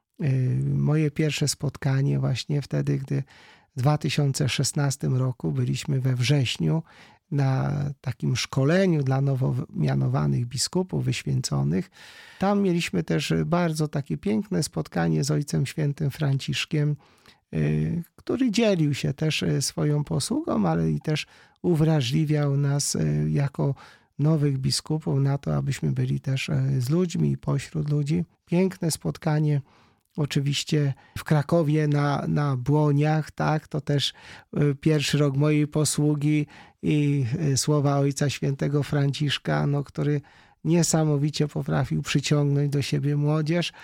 W rozmowie z Radiem Rodzina opowiedział nie tylko o wspomnieniach jakie pozostaną w nim po papieżu, ale też o przesłaniu jego pontyfikatu.